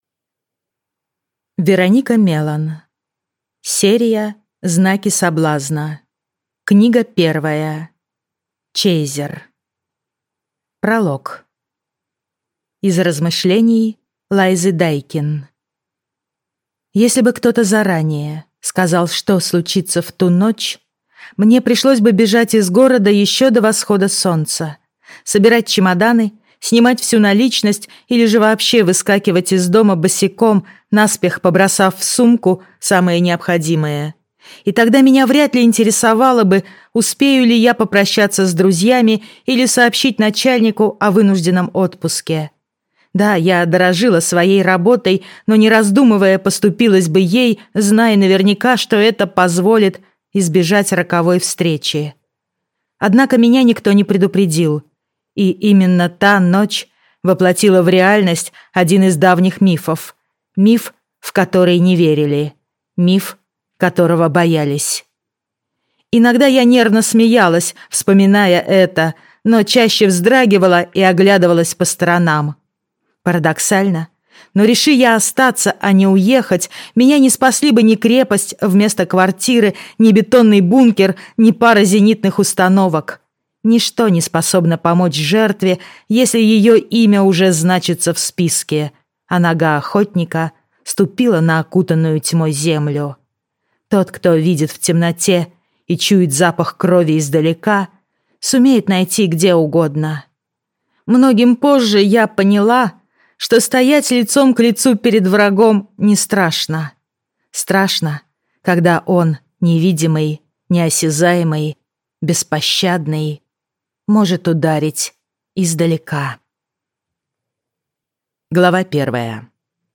Аудиокнига Чейзер - купить, скачать и слушать онлайн | КнигоПоиск